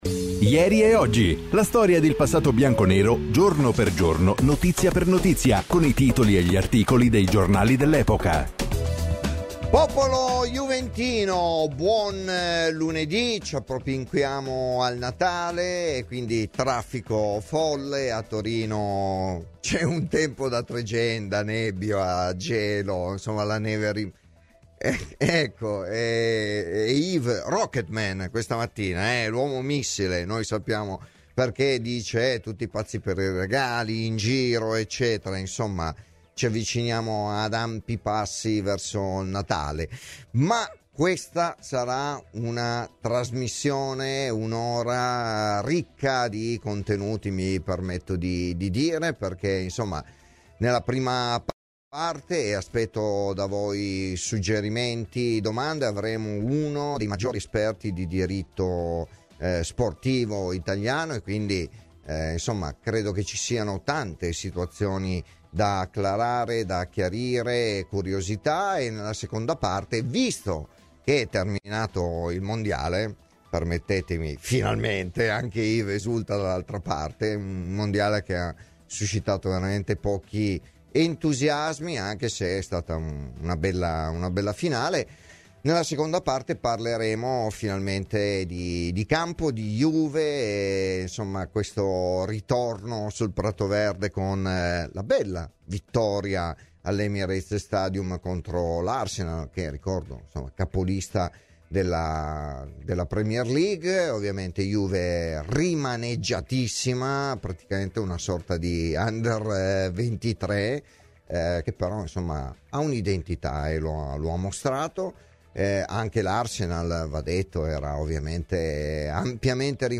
Intervenuto ai microfoni di Radio BiancoNera